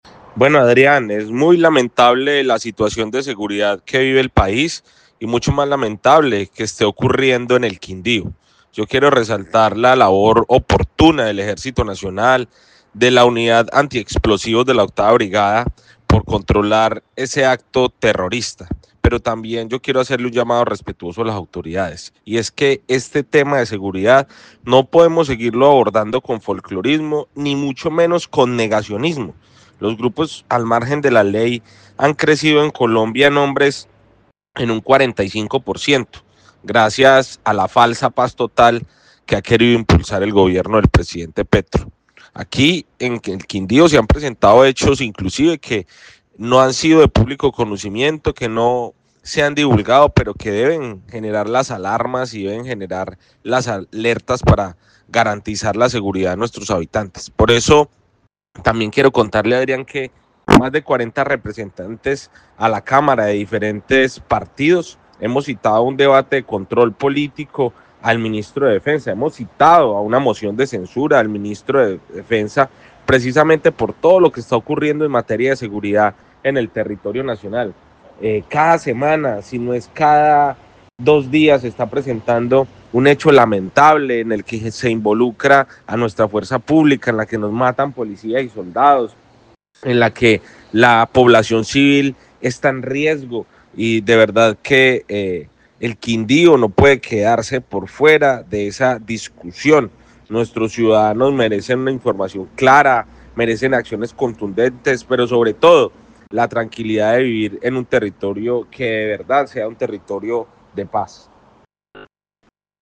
Jhon Edgar Pérez, Representante a la Cámara por el Quindío